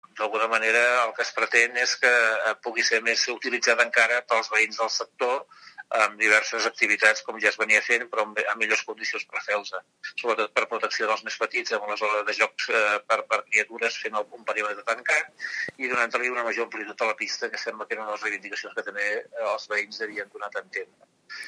D’altra banda, ja han començat les obres de remodelació de la Plaça Vila Romà que té un període de 4 mesos d’execució. El regidor d’urbanisme, Jordi Pallí, ha explicat que serà molt més “diàfana” i amb més zones per a la mainada.